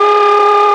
radar_lock.wav